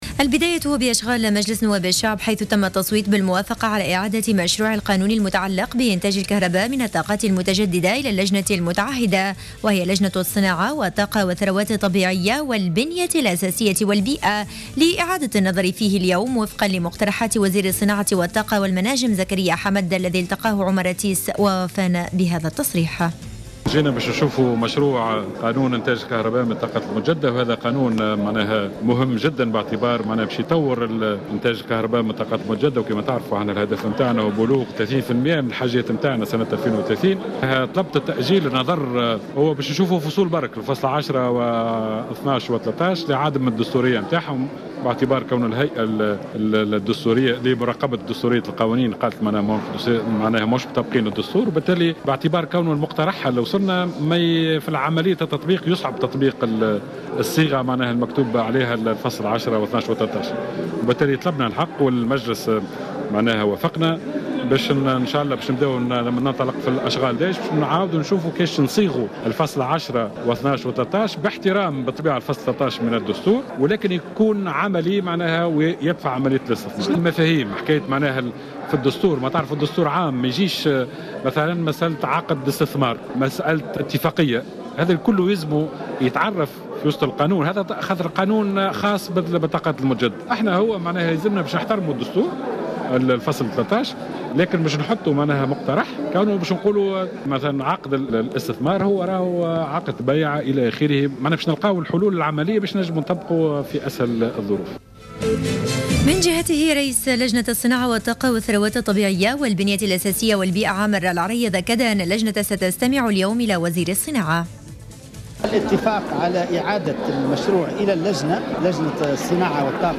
نشرة أخبار منتصف الليل ليوم الخميس 2 أفريل 2015